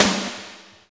45.01 SNR.wav